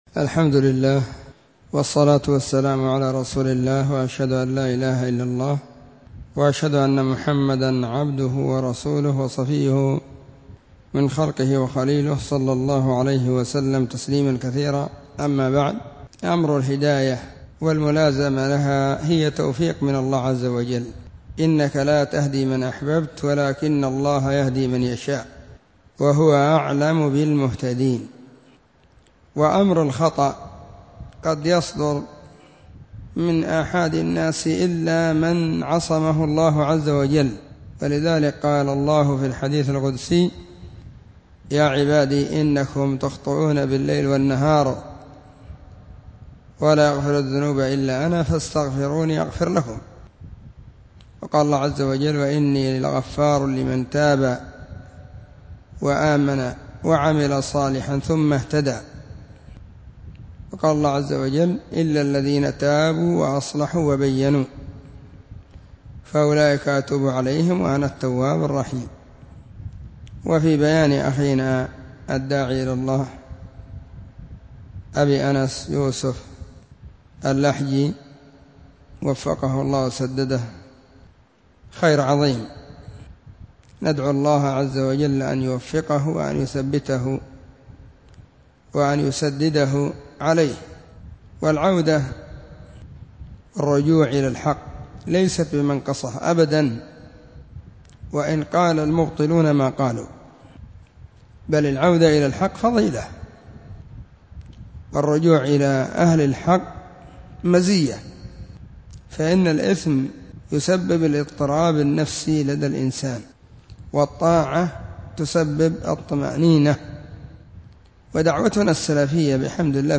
📢 مسجد – الصحابة – بالغيضة – المهرة، اليمن حرسها الله.
السبت 9 صفر 1442 هــــ | كلمــــات | شارك بتعليقك